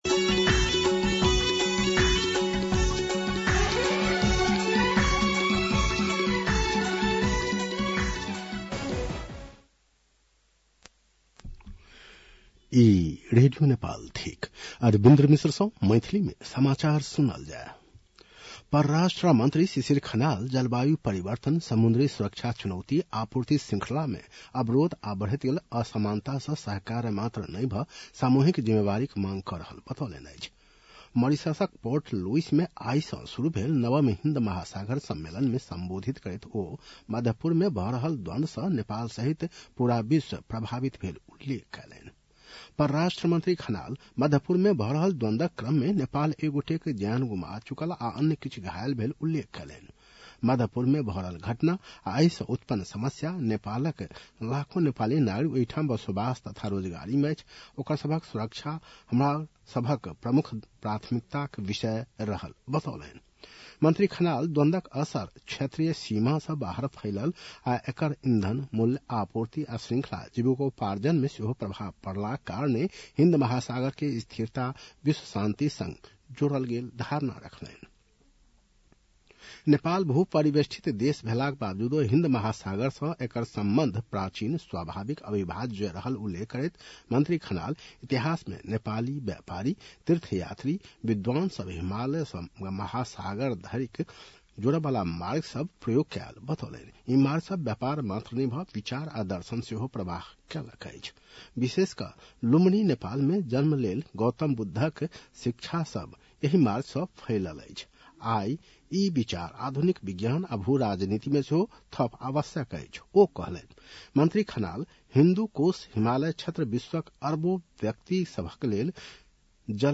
मैथिली भाषामा समाचार : २८ चैत , २०८२
6.-pm-maithali-news-1-2.mp3